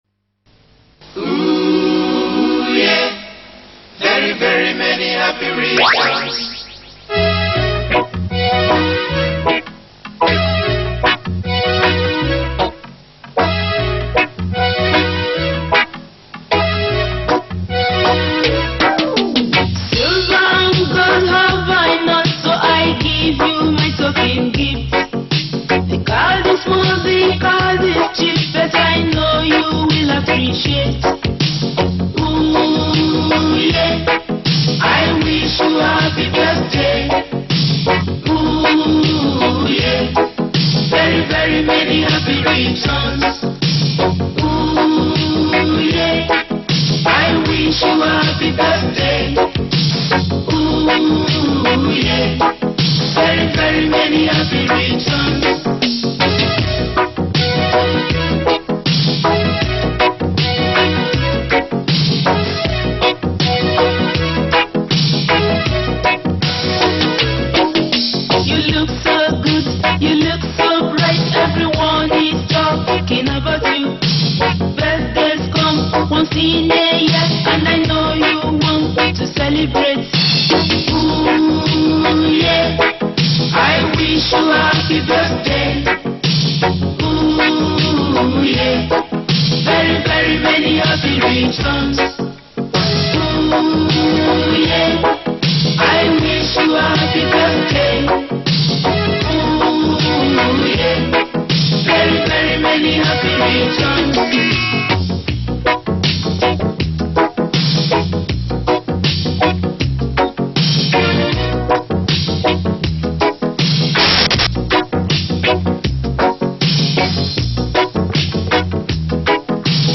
Nigeria Gospel Music